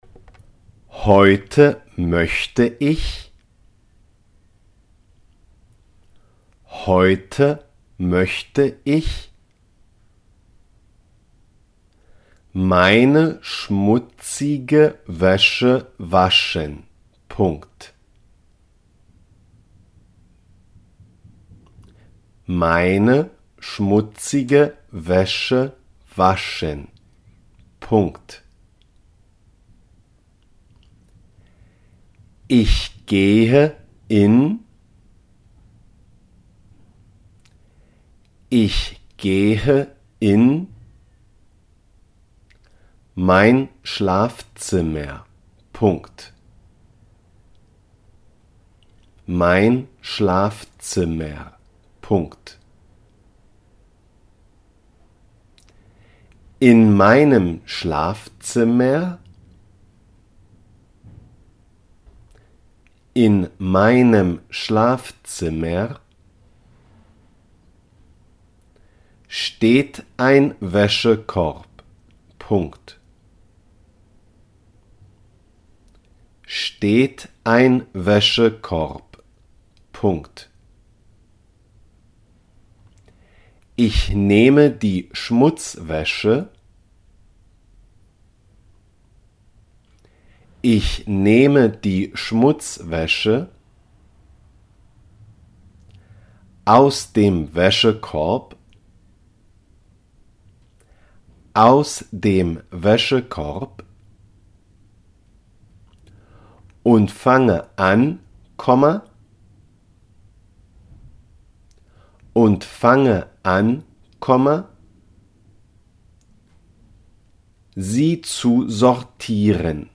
schmutzige Wäsche – la ropa sucia .dictado
La-ropa-sucia-schmutzige-Wasche-dictado-en-aleman-Aprend-1.mp3